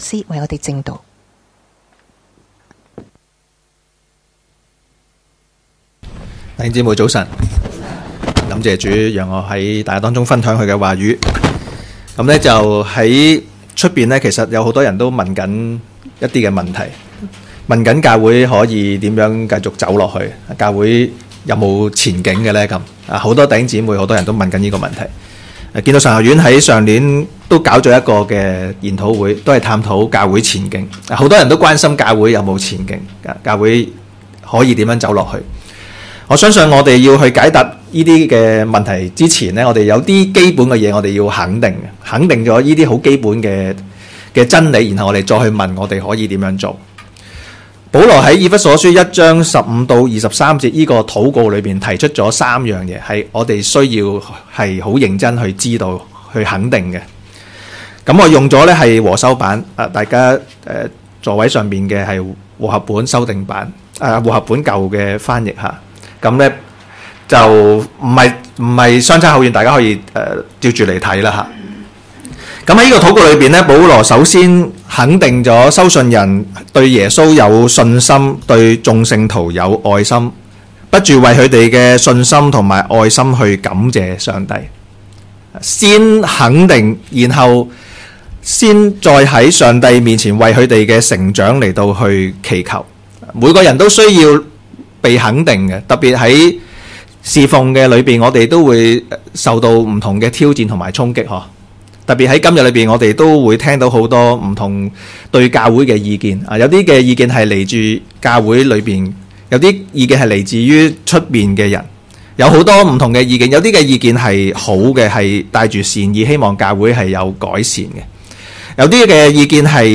2023年12月16日及17日崇拜
講道 ：信徒必須知道的三件事